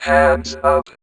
VVE1 Vocoder Phrases
VVE1 Vocoder Phrases 24.wav